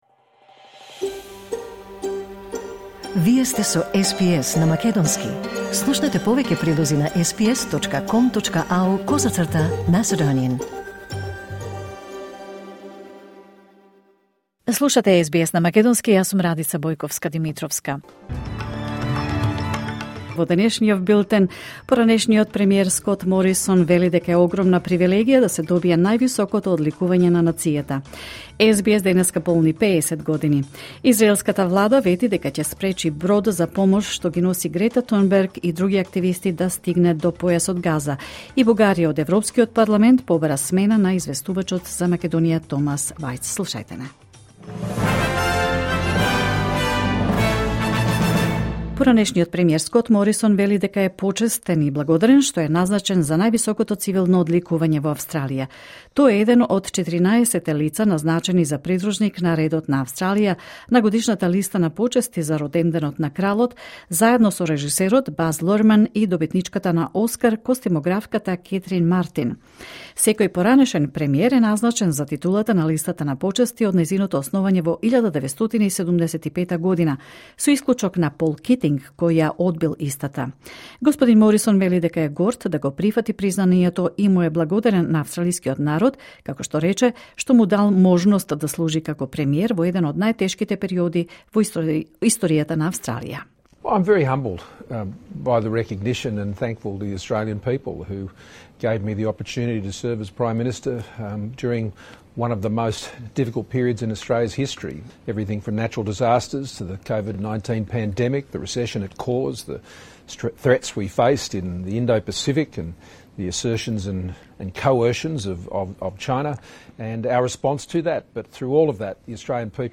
Вести на СБС на македонски 9 јуни 2025